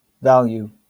wymowa amerykańska?/i
IPA/ˈvæl.juː/